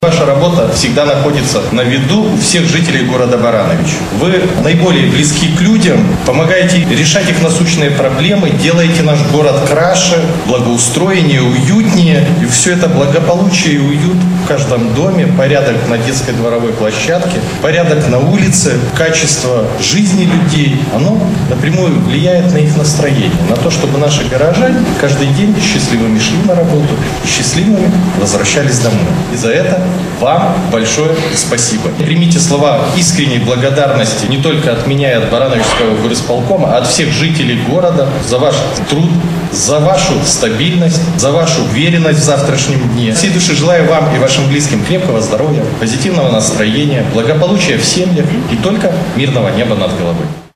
Торжественное мероприятие развернулось в стенах Дворца детского творчества. Слова благодарности за труд собравшимся выразил председатель Барановичского горисполкома. Максим Антонюк отметил, что это праздник огромного количества людей – сантехников, электриков, слесарей, ремонтников – тех, благодаря кому в дома поступает тепло, а на улицах чистота и порядок.